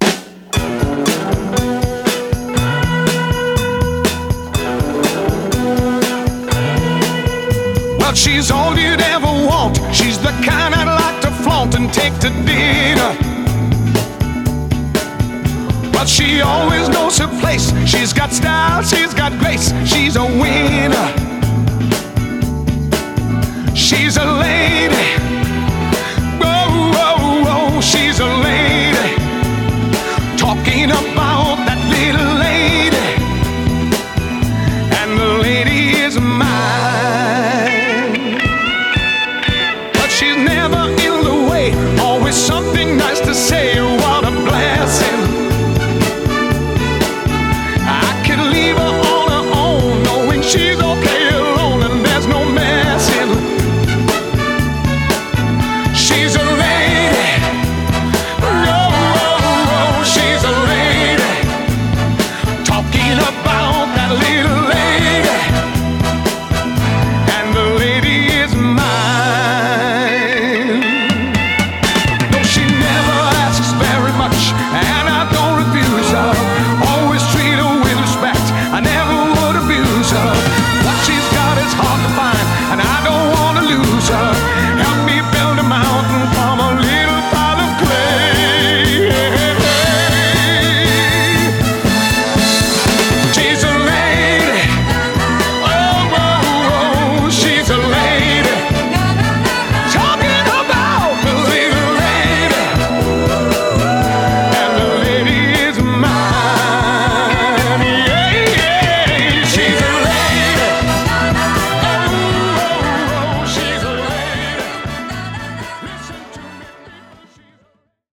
BPM117-124
Audio QualityMusic Cut